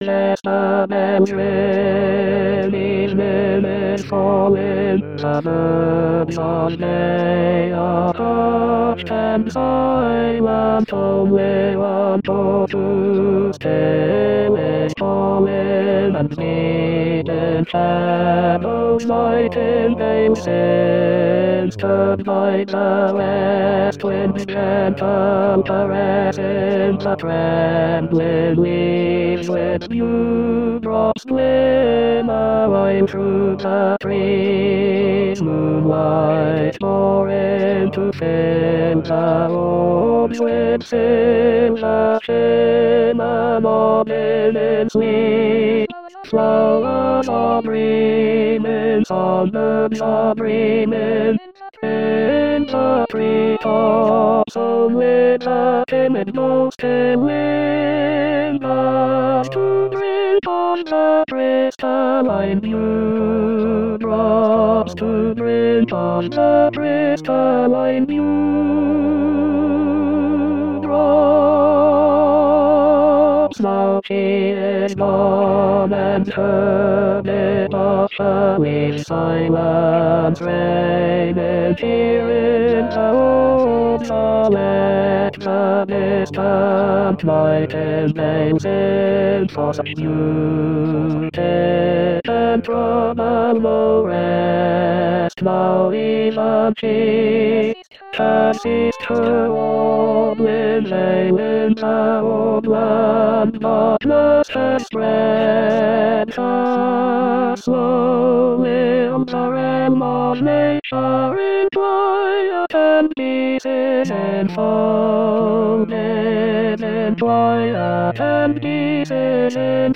Tenor Tenor 2